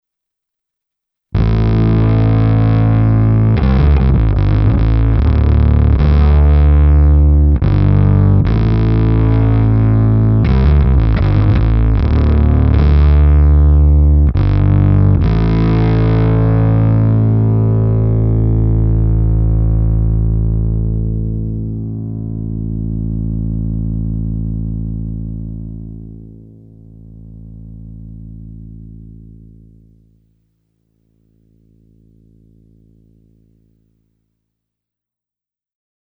Zvuk je všechno, jen ne hezký.
Ten zvuk má být takhle hnusný. Agresívní, chlupatý, chrčivý.
Pěkně zpracovaná krabička, povedený design, široké zvukové možnosti, kde převažuje mocný fuzz.